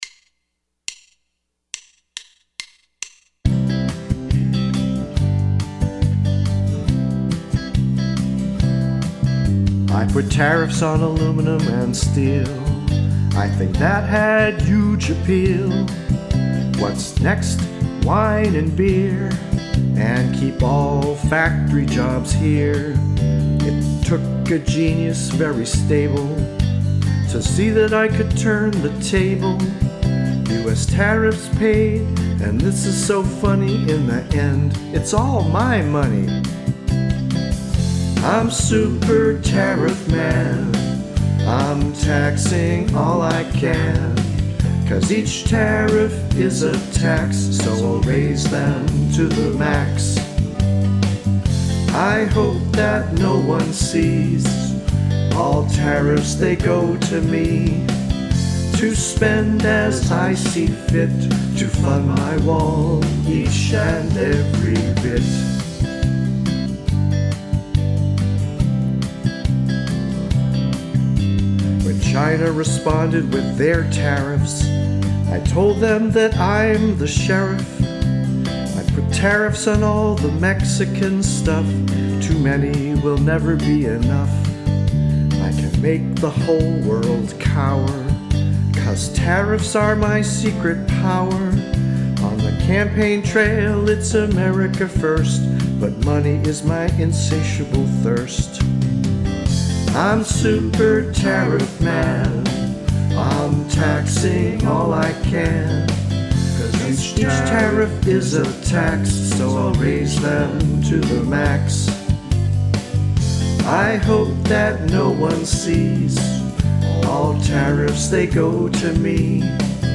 demo recording.